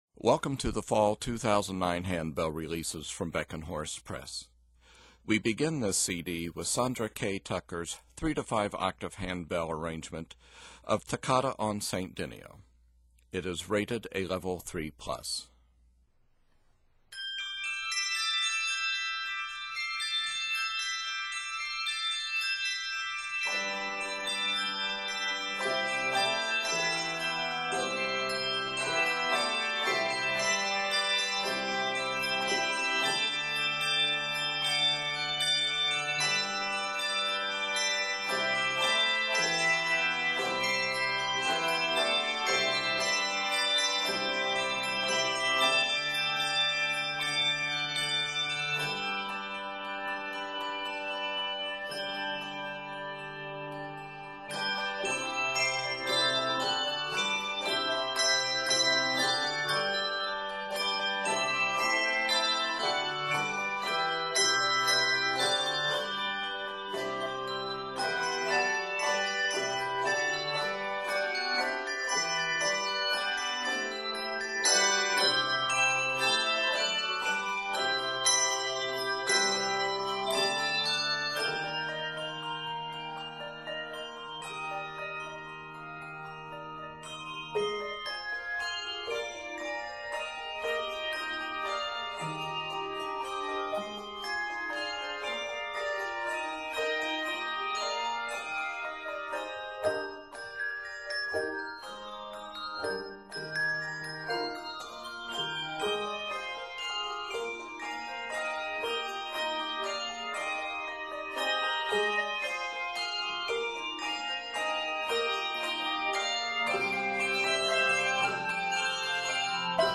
The energy of this bold piece drives to the end.